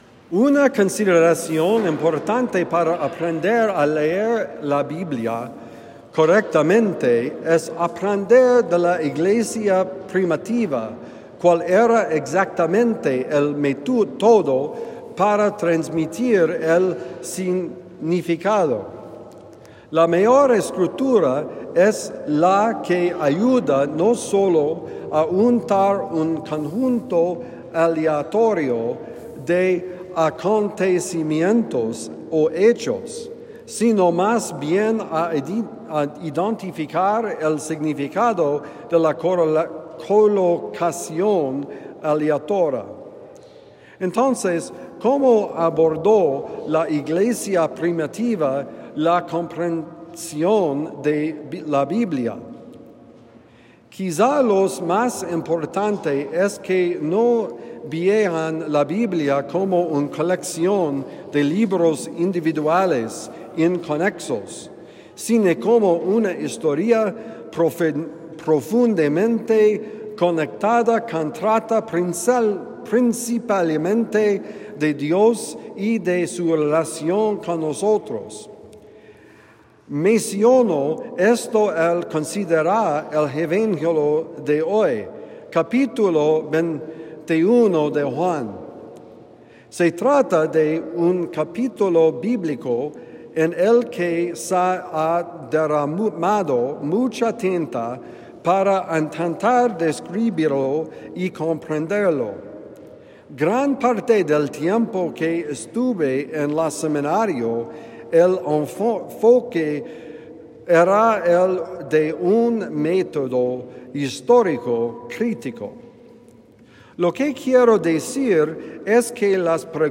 El poder de la Resurrección: Homilía del domingo 4 de mayo de 2025 – The Friar